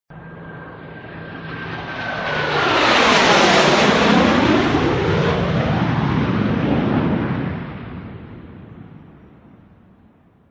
Download Plane sound effect for free.
Plane